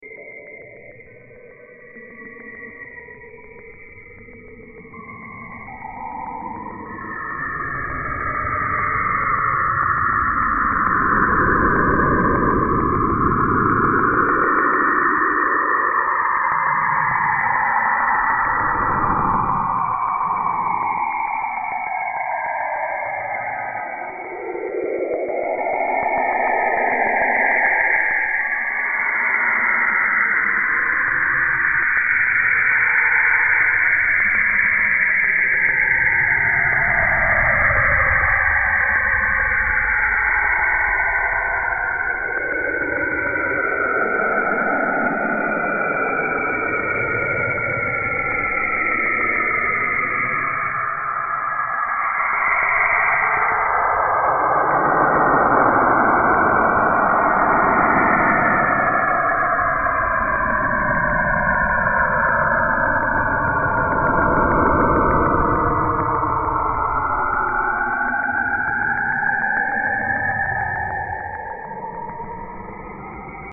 Пугающие звуки, страх